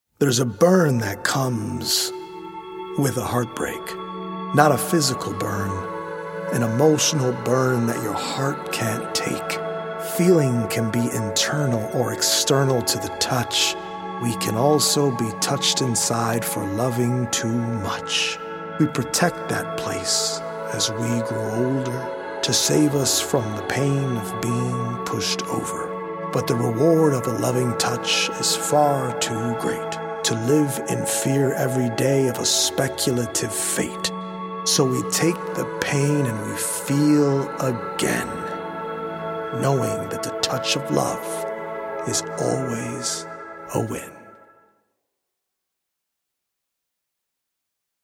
based on 100 original poems written/performed
healing Solfeggio frequency music
EDM producer